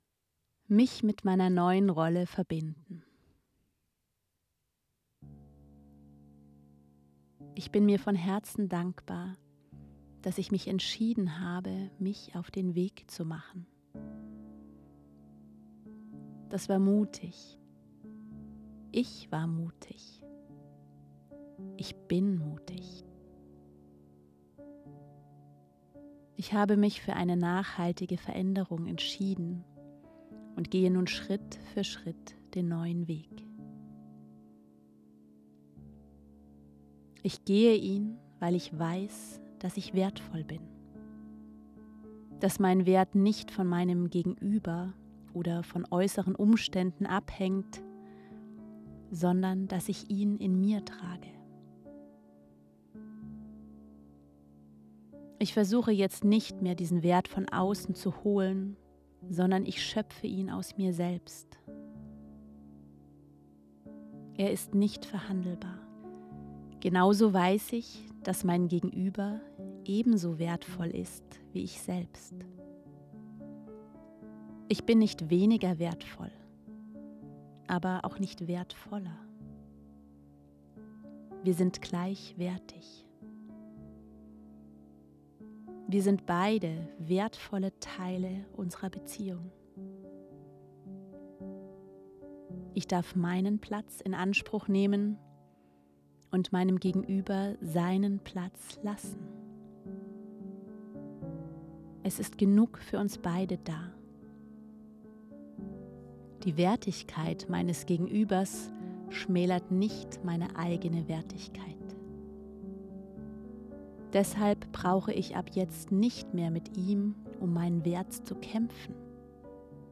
Gedankenreise: Rolle verbinden